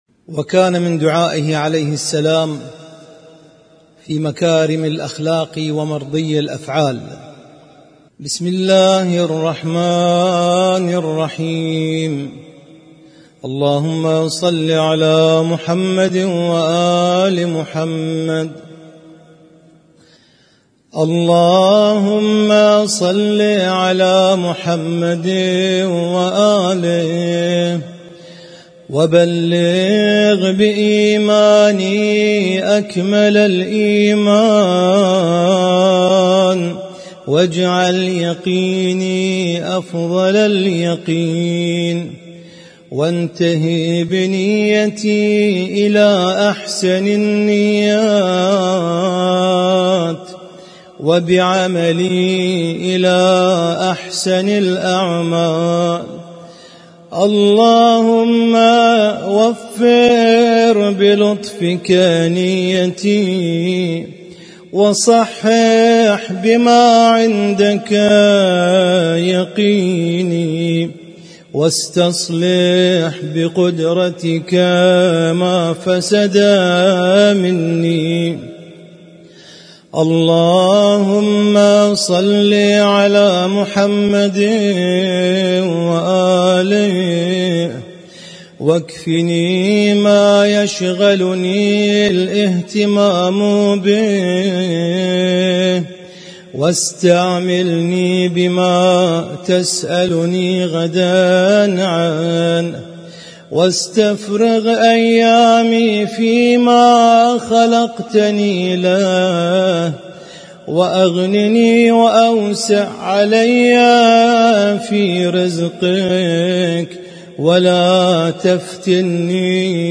اسم التصنيف: المـكتبة الصــوتيه >> الصحيفة السجادية >> الادعية السجادية